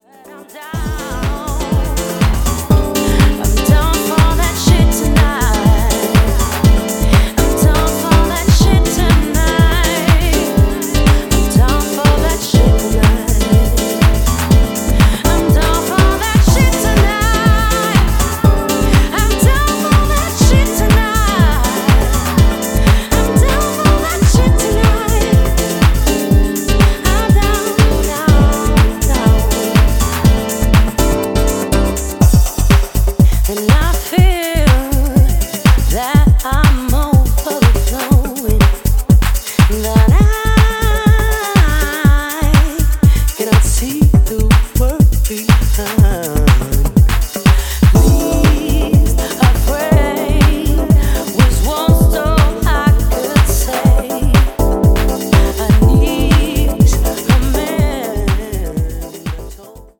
ピアノ・リフも効かせながらヴォーカル・ハウスを展開していった